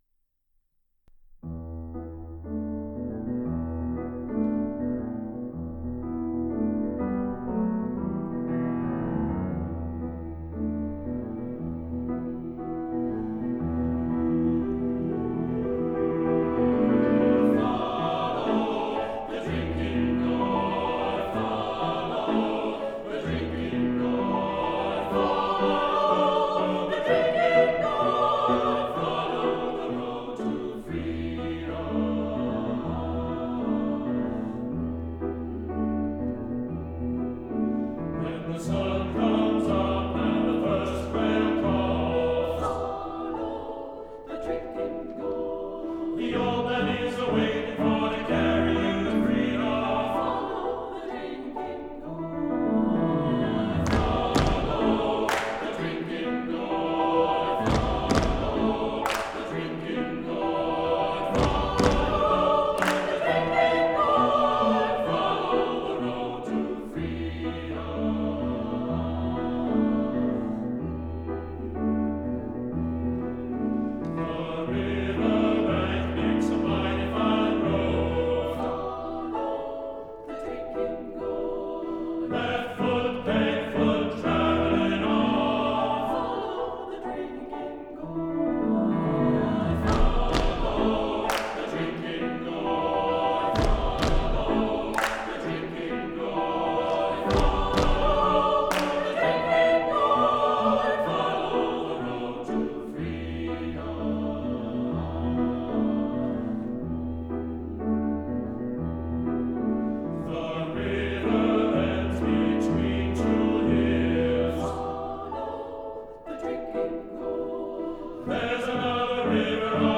for SATB Chorus and Piano (2003)
Footsteps, hand-claps (for joy) and "shooting stars" appear.